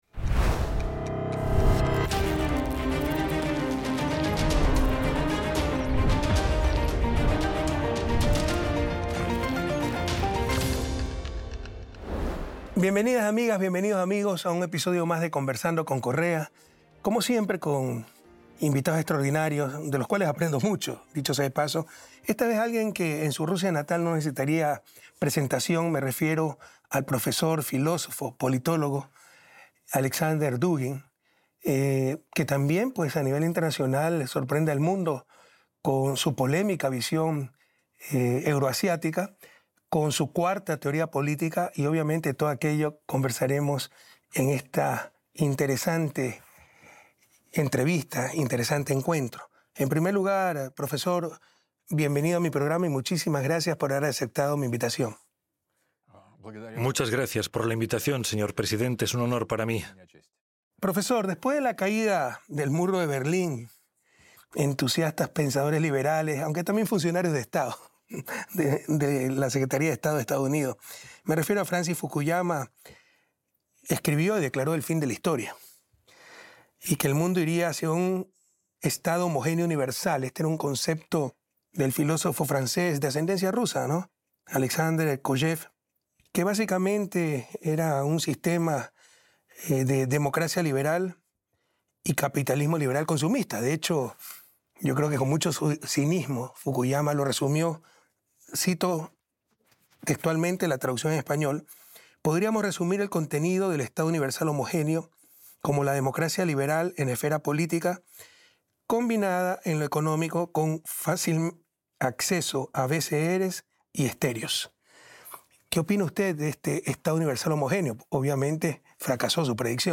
Intervistas de Dugin